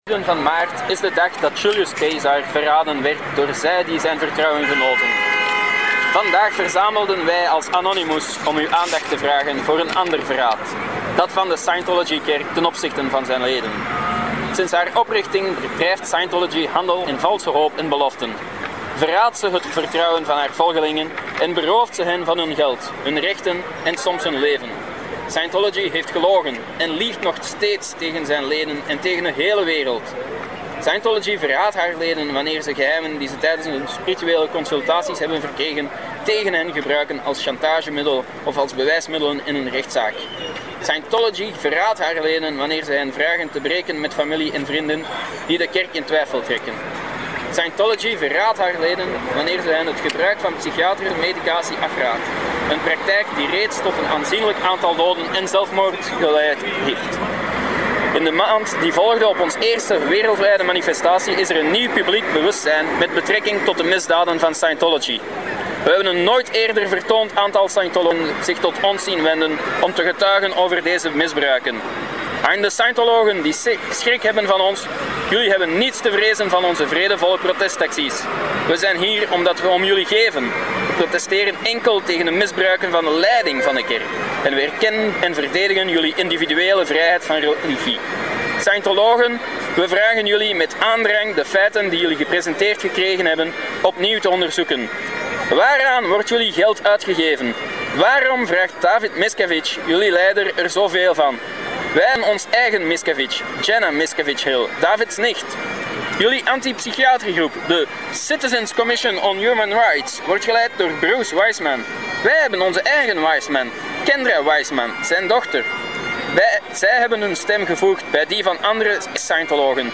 Actie aan het Scientology hoofdkwartier in de Wetstraat 91, onder het mom van een 'opendeurdag'.
De internationaal voorbereide speech van Anonymous.
Brussels Anonymous protest Scientology - speech.mp3